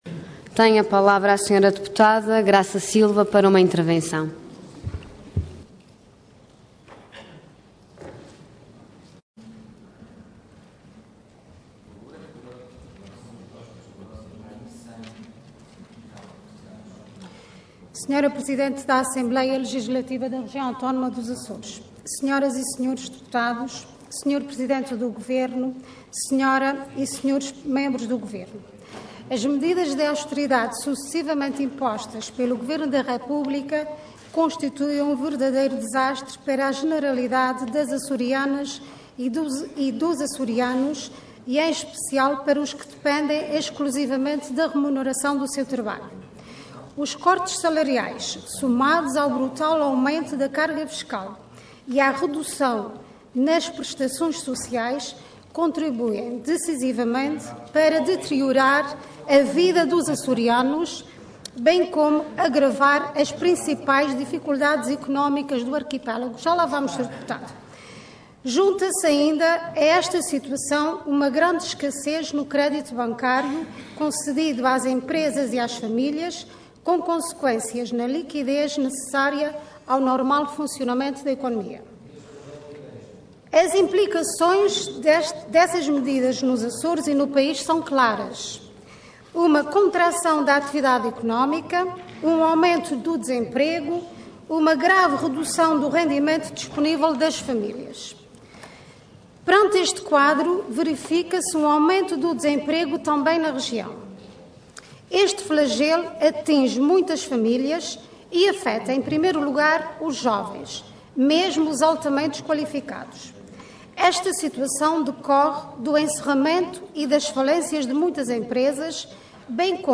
Intervenção Intervenção de Tribuna Orador Graça Silva Cargo Deputada Entidade PS